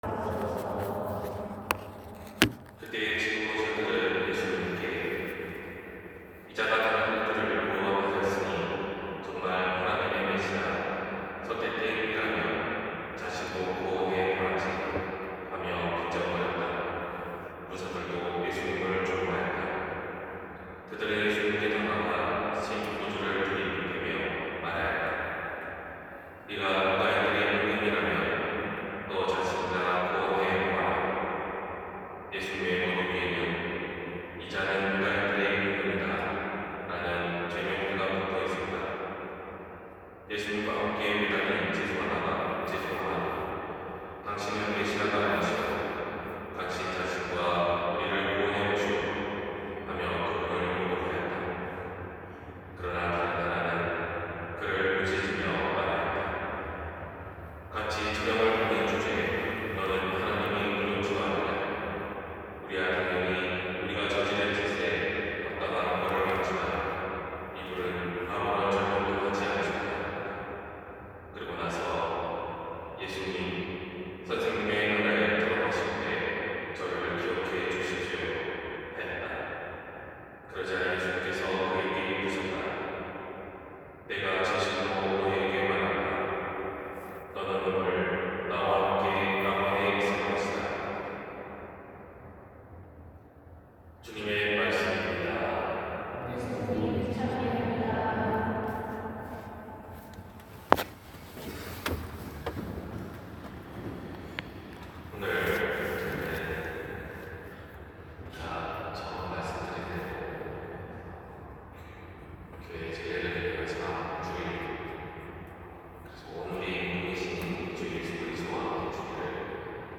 251122신부님 강론말씀